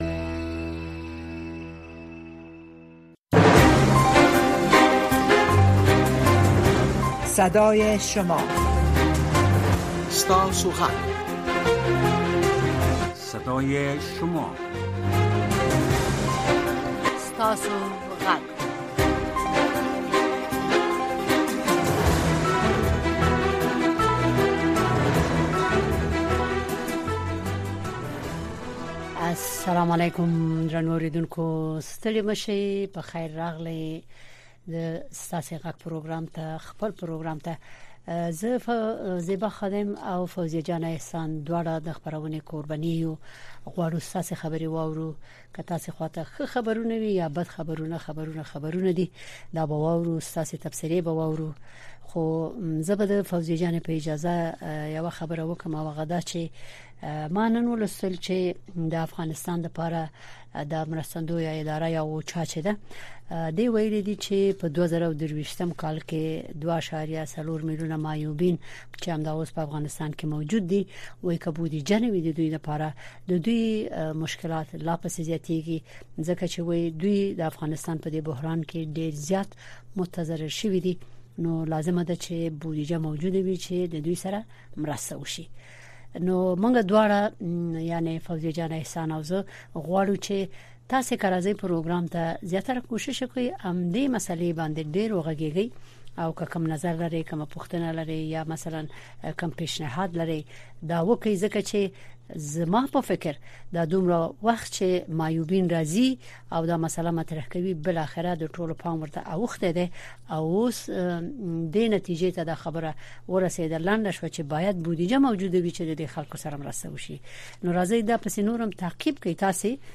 ستاسې غږ خپرونه کې د امریکا غږ د اشنا رادیو اوریدونکي په مستقیمه توګه له مونږ سره اړیکه نیسي او د خپرونې د چلوونکو او اوریدونکو سره خپل نظر، اندیښنې او شکایتونه شریکوي. دا خپرونه په ژوندۍ بڼه د افغانستان په وخت د شپې د ۹:۳۰ تر ۱۰:۳۰ بجو پورې خپریږي.